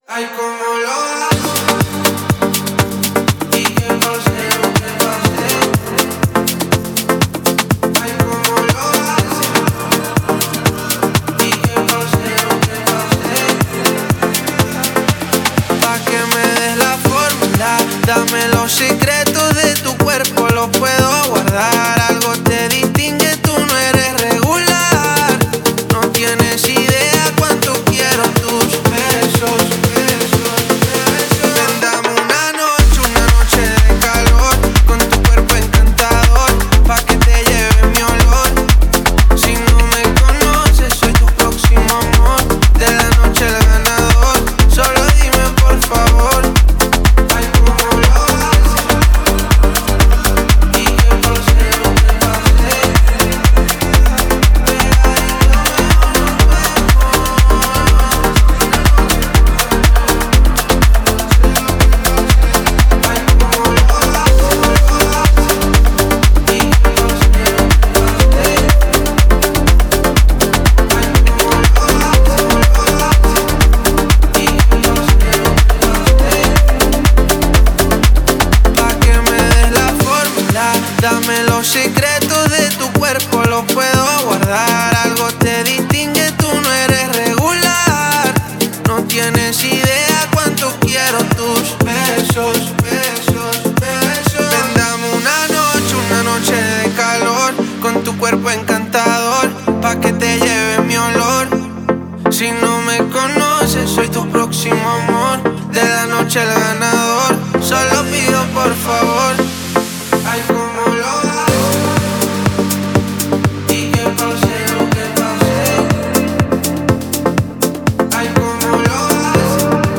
Жанр: Pop, Dance